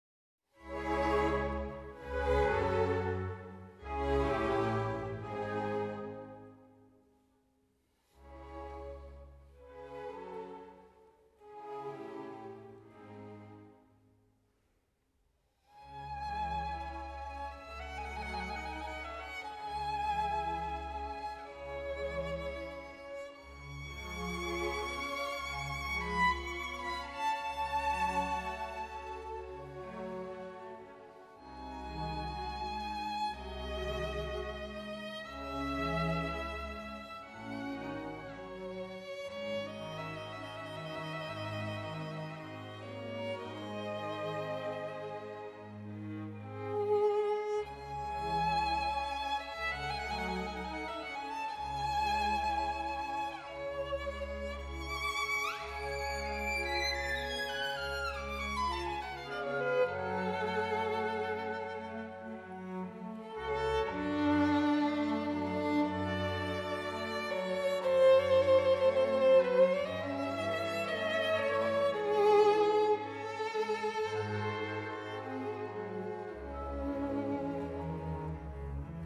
Polska muzyka wirtuozowska
Opracowanie na skrzypce i orkiestrę